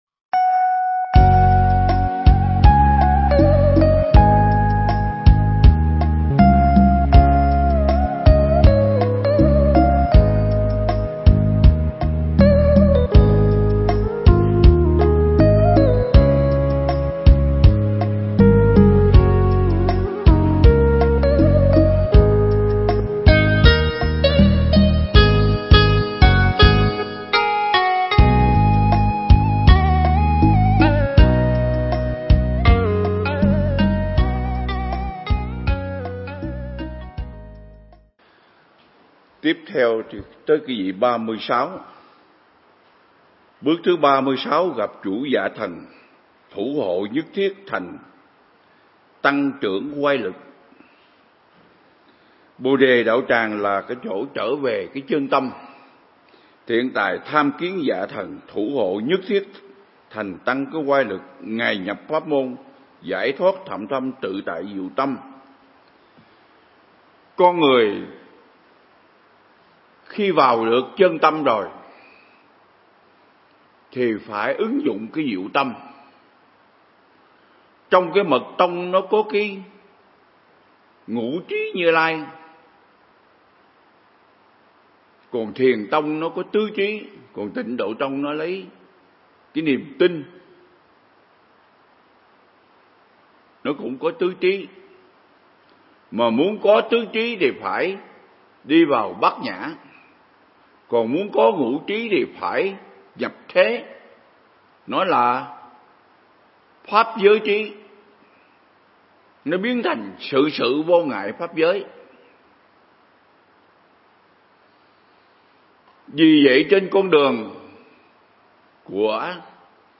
Mp3 Pháp Thoại Ứng Dụng Triết Lý Hoa Nghiêm Phần 57
giảng tại Viện Nghiên Cứu Và Ứng Dụng Buddha Yoga Việt Nam (TP Đà Lạt)